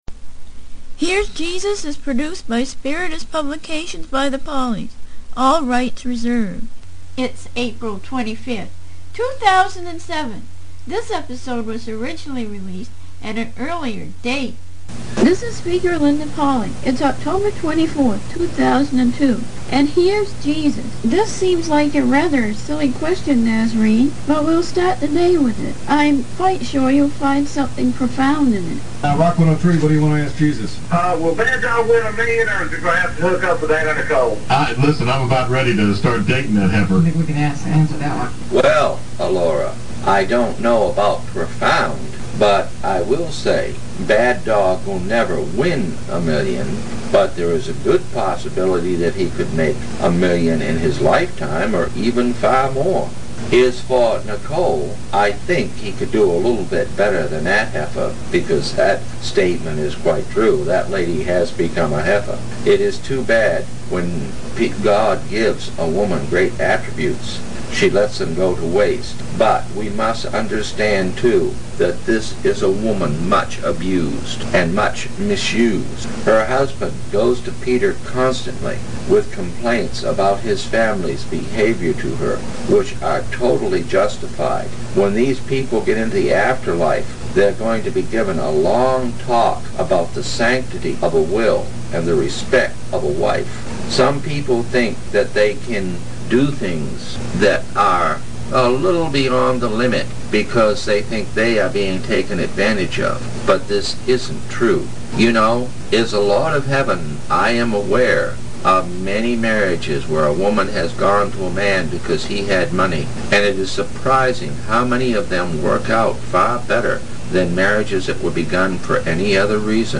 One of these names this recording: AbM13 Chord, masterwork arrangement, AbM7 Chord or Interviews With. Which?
Interviews With